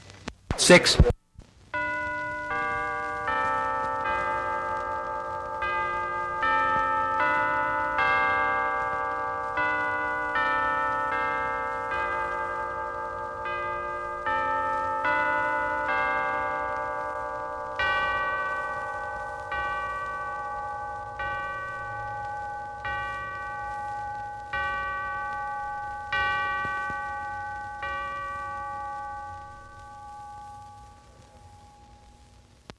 复古氛围 " G1713在码头工作
描述：一般运动和码头上的工作。脚步和金属物体的运动。可以用于工厂。 这些是20世纪30年代和20世纪30年代原始硝酸盐光学好莱坞声音效果的高质量副本。 40年代，在20世纪70年代早期转移到全轨磁带。我已将它们数字化以便保存，但它们尚未恢复并且有一些噪音。
Tag: 工业 眼镜 复古 环境